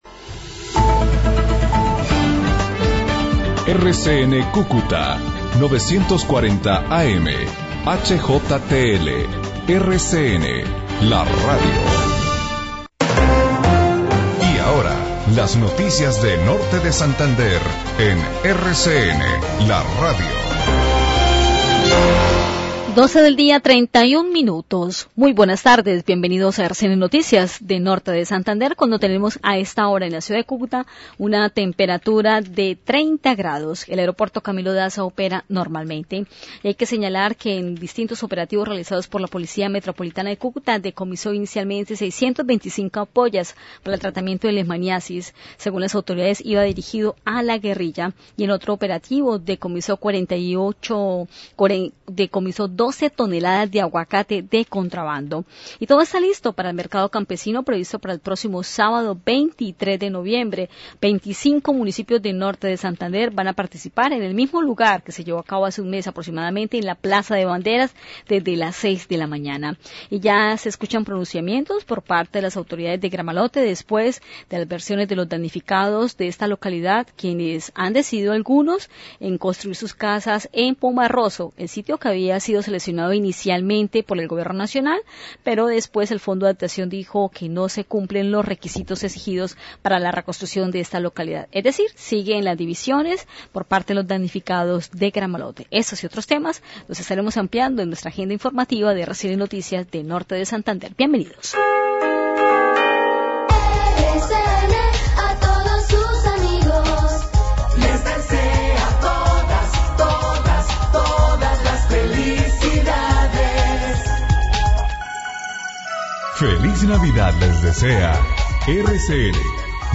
originadas desde los estudios de RCN Radio 940AM